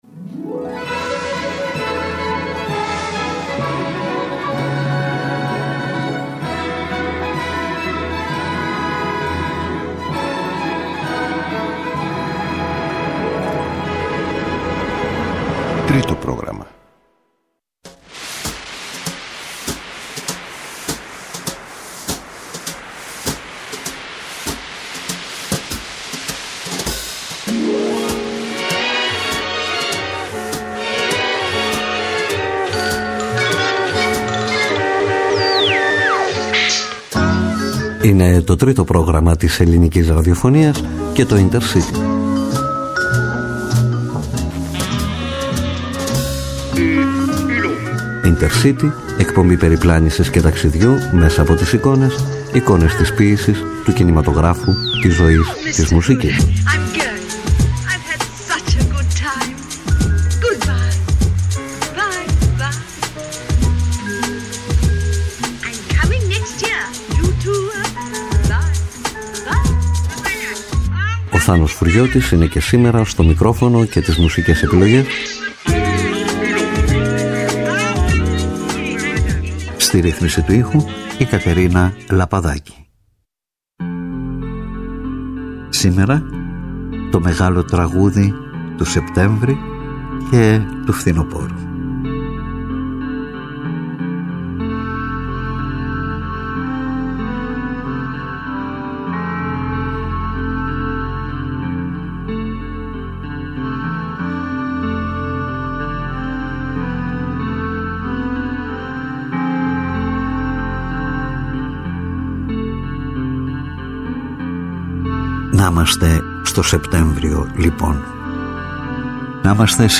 ———————————————————————————————————————— Εκπομπή περιπλάνησης και ταξιδιού μέσα από τις εικόνες : εικόνες της ποίησης, του κινηματογράφου, της ζωής, της μουσικής Με αφορμή ένα θέμα, μια σκέψη, ένα πρόσωπο, ένα βιβλίο , μια ταινία , ένα γεγονός ανακατεύουμε ντοκουμέντα, σελίδες βιβλίων, κείμενα , ήχους, μουσική , λόγο και πάμε ταξίδι σε παρελθόν, παρόν και μέλλον.